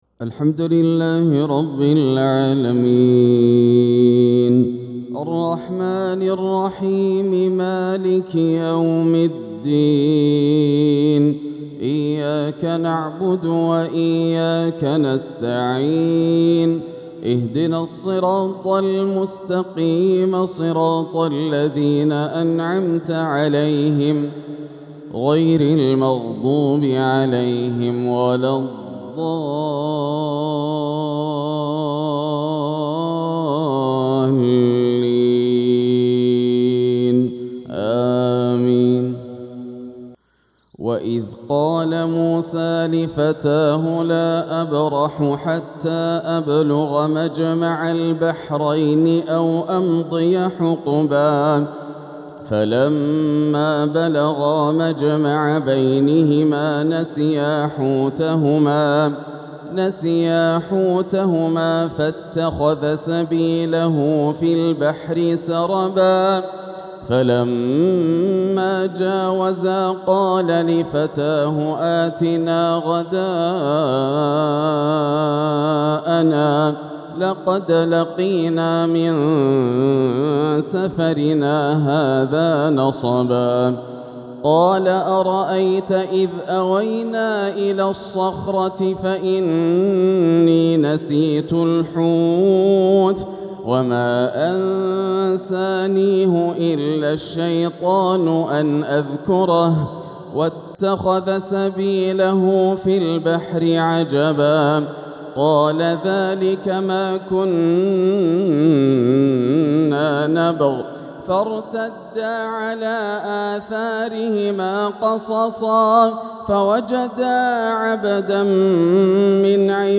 تلاوة خاشعة من سورة الكهف | فجر الجمعة 6-7-1447هـ > عام 1447 > الفروض - تلاوات ياسر الدوسري